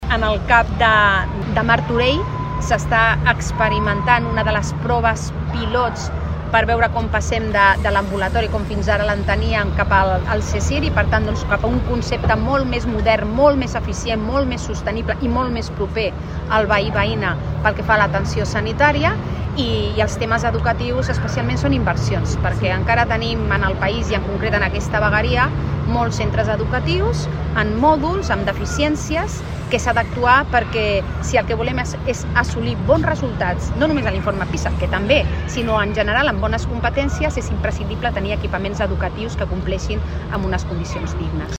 Pilar Díaz, delegada del Govern Generalitat a Barcelona